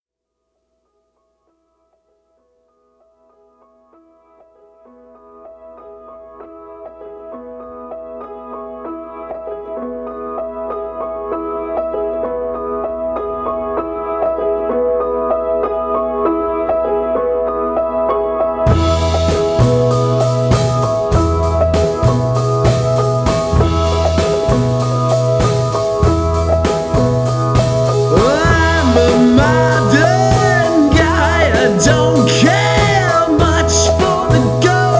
Жанр: Рок / Альтернатива
Alternative, Indie Rock, Rock, College Rock